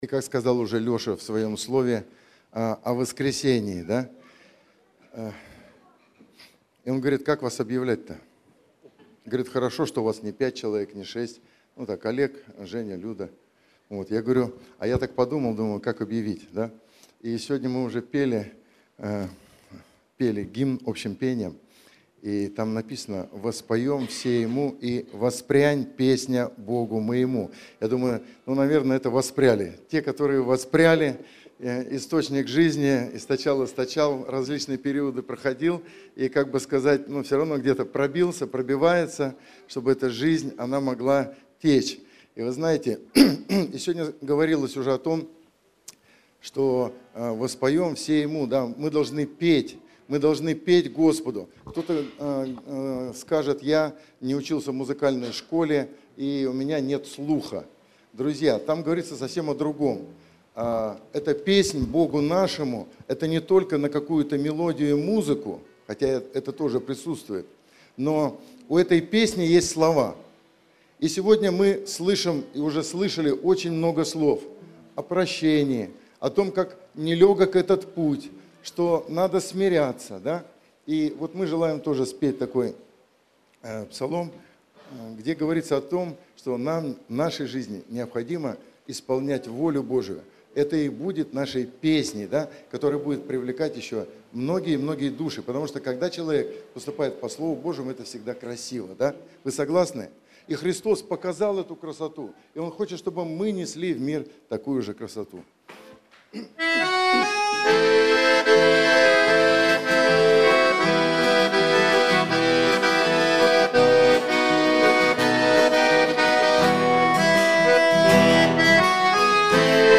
Богослужение 06.10.2024
Огонь не гасится огнем - Источник жизни (Пение)[